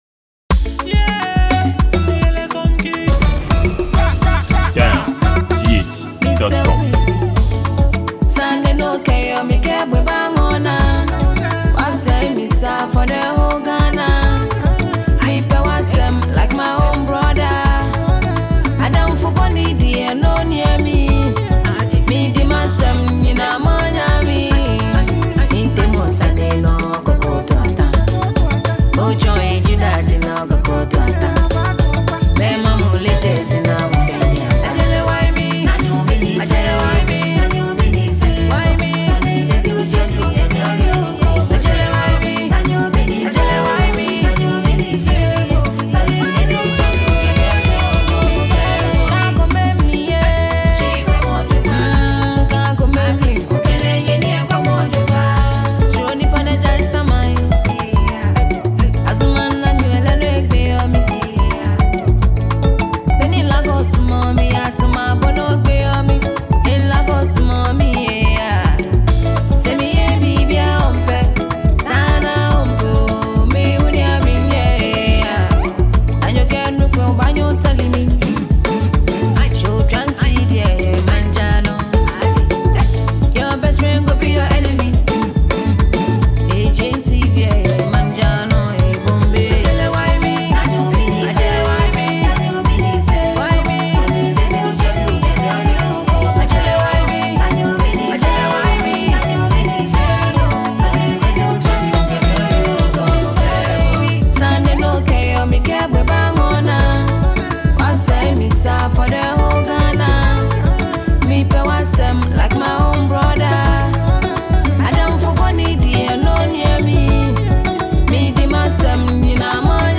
Female Ghanaian singer and a social media celebrity
This is a self-esteem and delightful song to the fans.